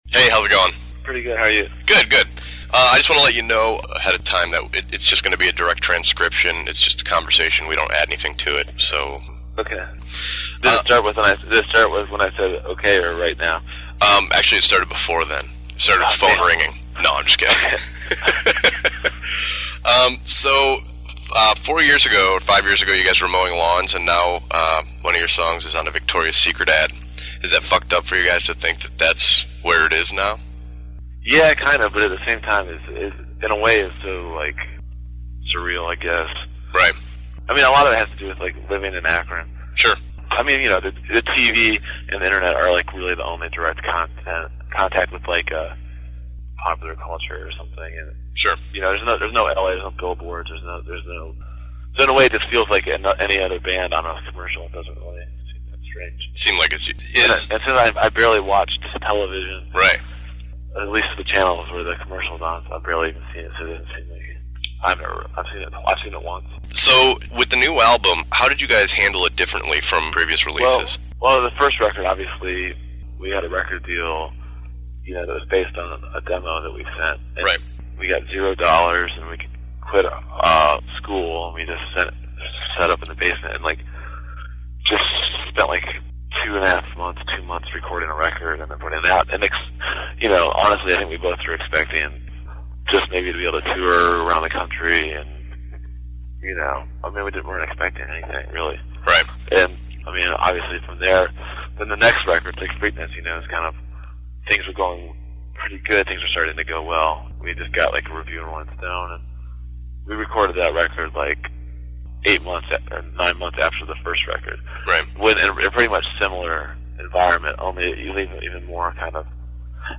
LAUNCH PATRICK CARNEY INTERVIEW (MP3 FILE)(NOTE: File is large and may take awhile to fully load.)